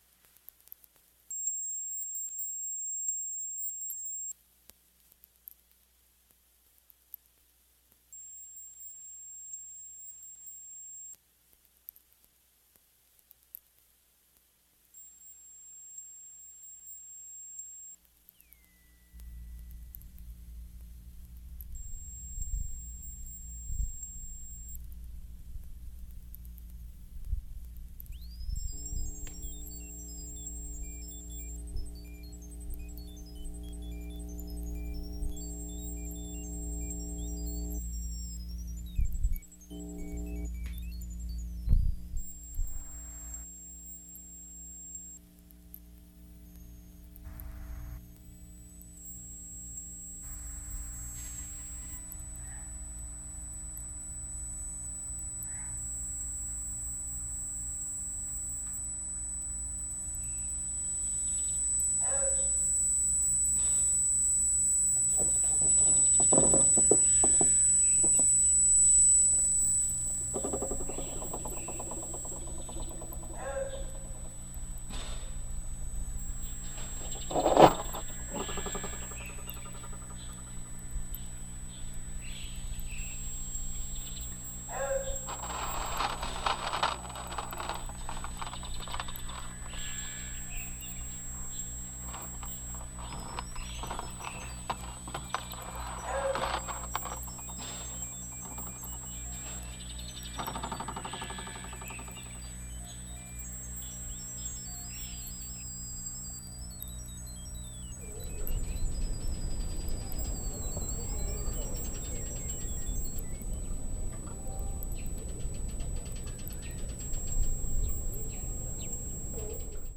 ともにフィールド録音などを用いながらの純度の高い電子音作品となっています！ラップトップ的な質感も感じますね。